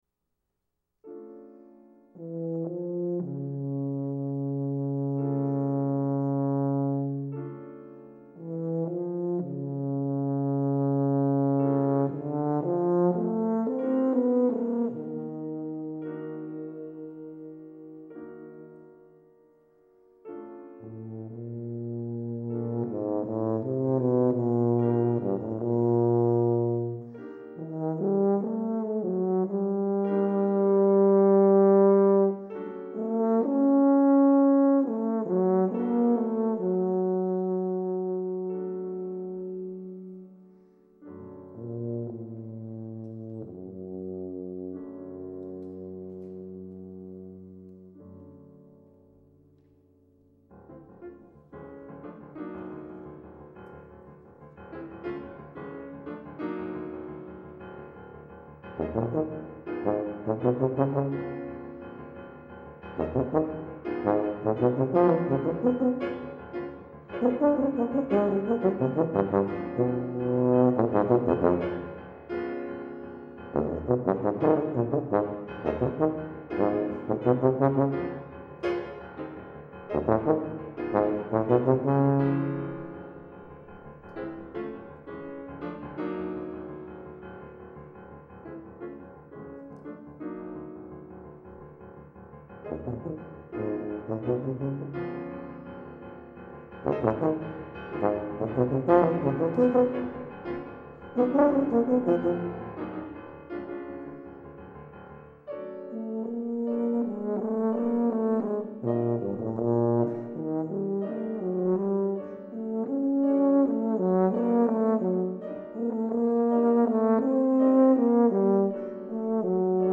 For Tuba Solo
Arranged by . with Piano.